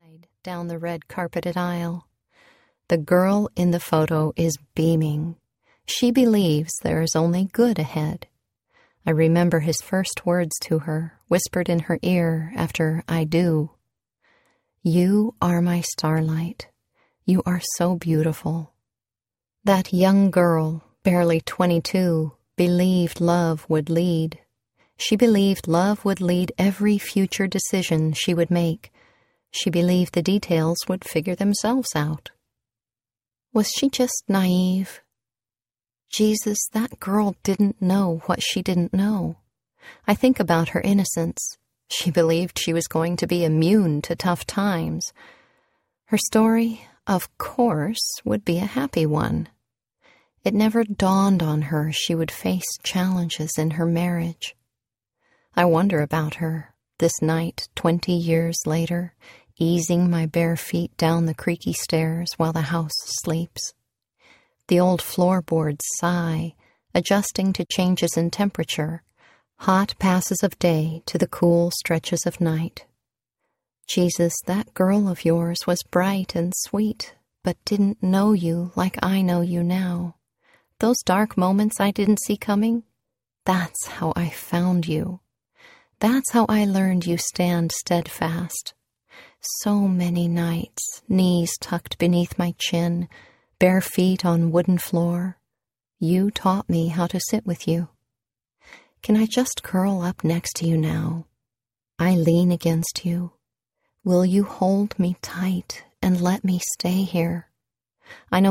Breathing Eden Audiobook